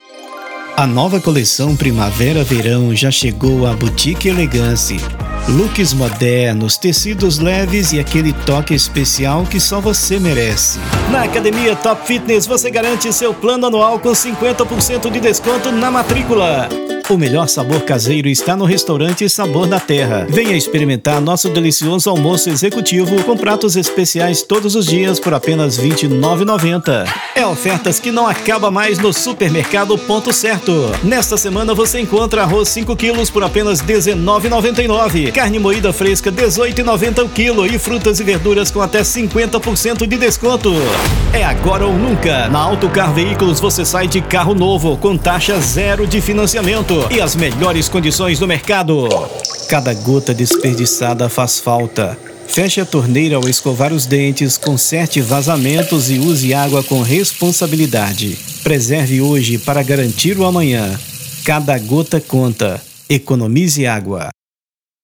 Minas Gerais
Spot Comercial
Vinhetas
Chamada de Festa
VT Comercial